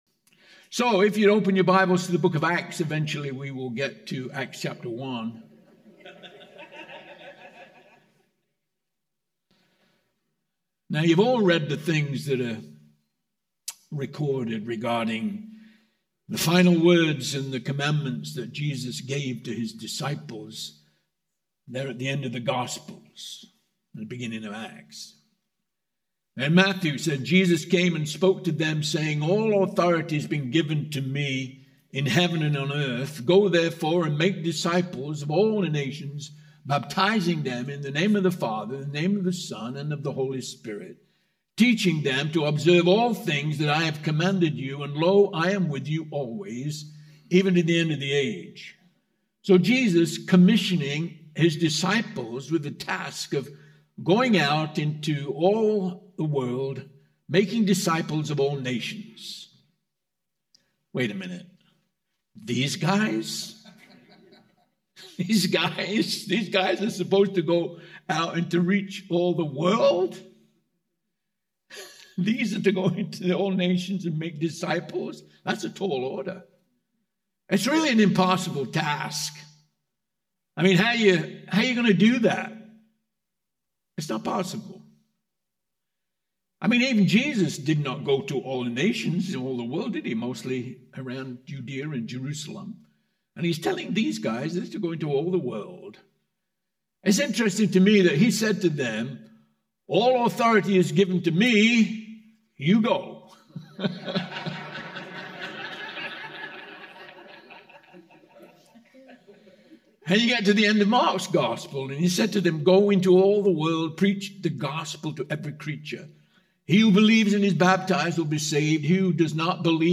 Home » Sermons » “The Powerless Pastor”
Conference: Pastors & Leaders